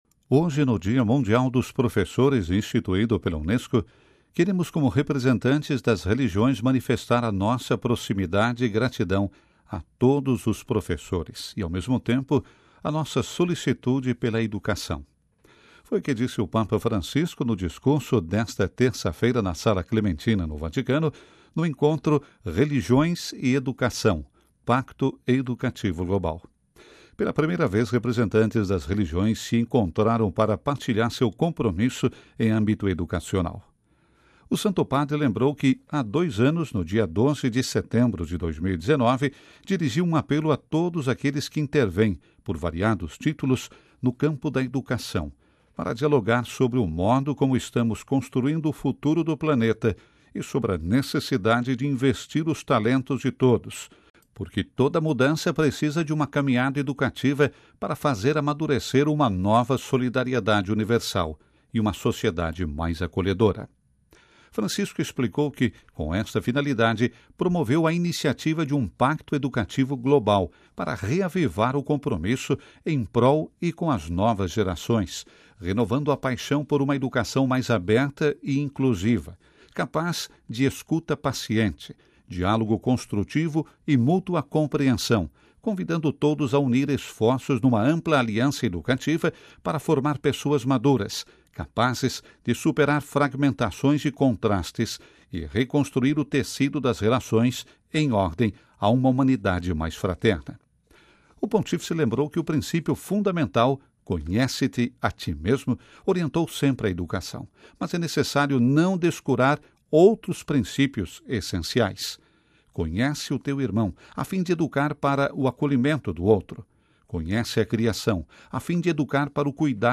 Ouça a reportagem com a voz do Papa Francisco
Foi o que disse o Papa Francisco no discurso esta terça-feira (05/10) na Sala Clementina, no Vaticano, no Encontro “Religiões e Educação: Pacto Educativo Global”.